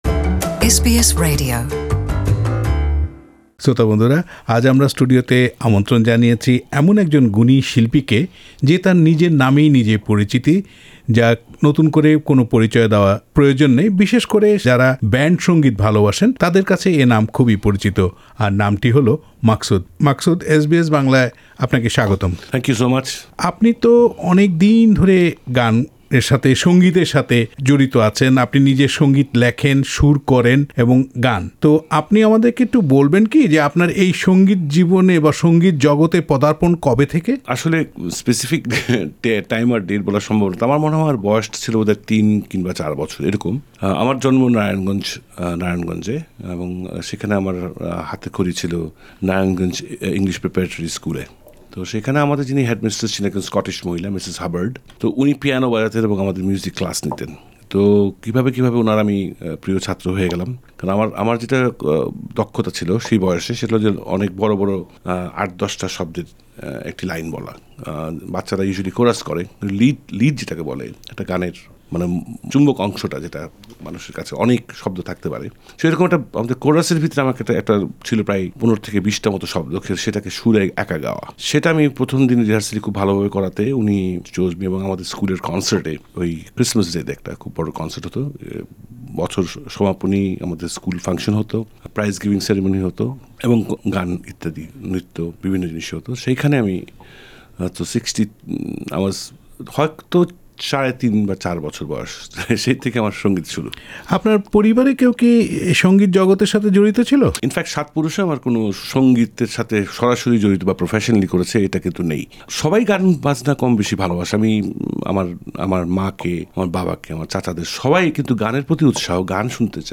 এসবিএস বাংলার সঙ্গে এক সাক্ষাৎকারে তিনি বলেন, বাউল সঙ্গীতের বিশাল সম্ভাবনা রয়েছে। বাউল গান সম্পর্কে আরও বলেন, গান এবং জ্ঞান দুটোই অর্জন করতে হবে।